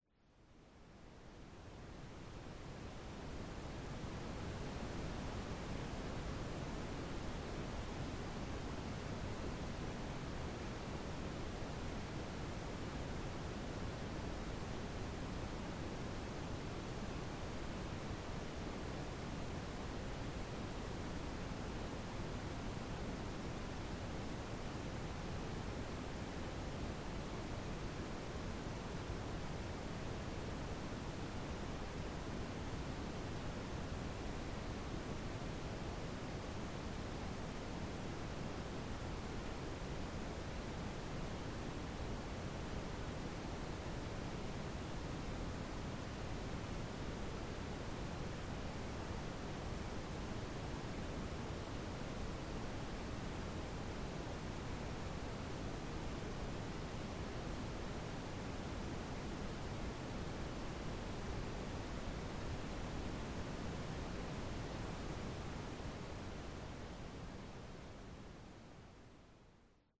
Calming nature recordings and ambient soundscapes.
Ocean Waves at Sunset
Duration: 1:10 · Type: Nature Recording · 128kbps MP3
Ocean_Waves_Sunset.mp3